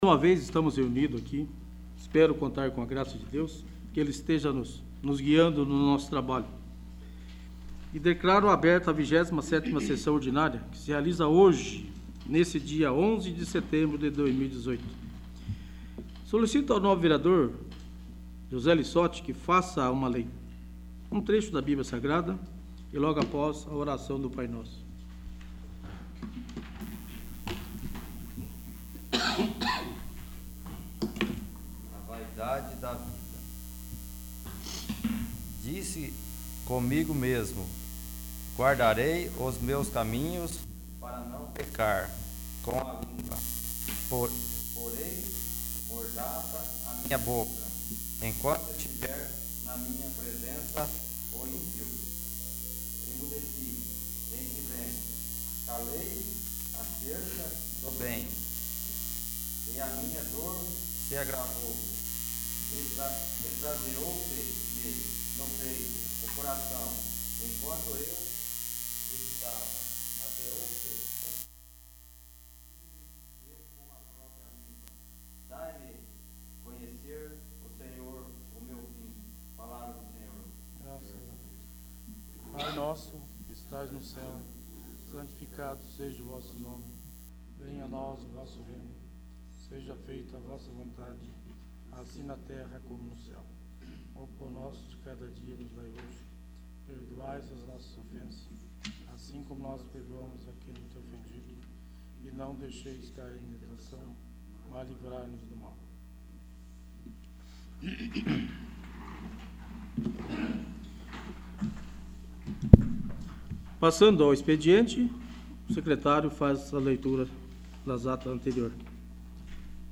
27º. Sessão Ordinária